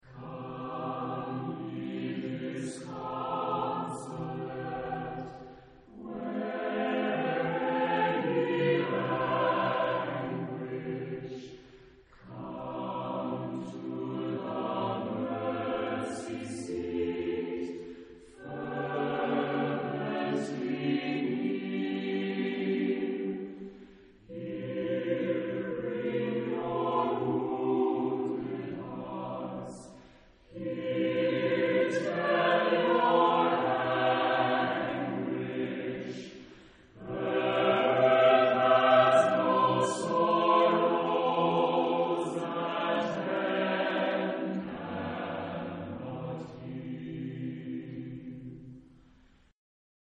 Epoque: 18th century
Genre-Style-Form: Sacred
Type of Choir: SATB  (4 mixed voices )
Instruments: Oboe (1) ; Organ (1)